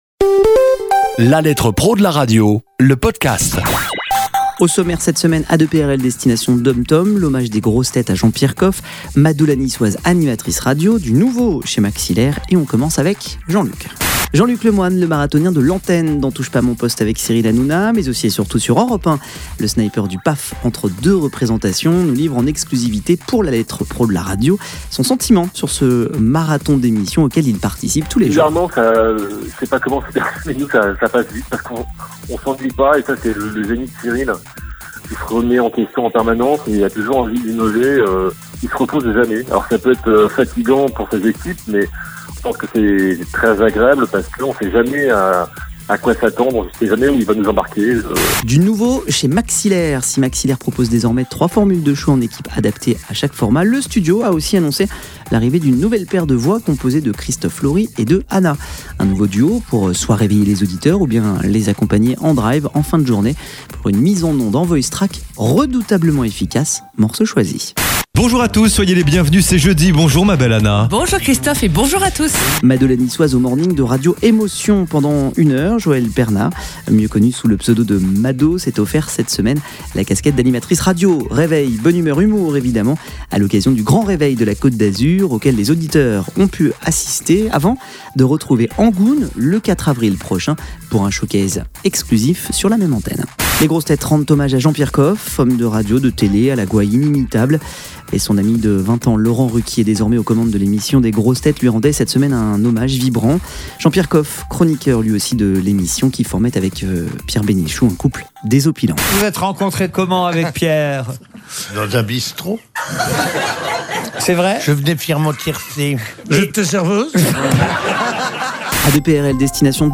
Un condensé de l'actualité de la semaine traitée ici ou dans le magazine. Cette capsule propose également des interviews exclusives de professionnels de la radio. Au sommaire cette semaine, l'interview exclusif de Jean-Luc Lemoine, l'hommage des Grosses Têtes à Jean-Pierre Coffe, Mado la Niçoise sur Radio Emotion, un nouveau duo chez Maxi L'Air et les flashs de l'A2PRL pour les stations des DOM TOM.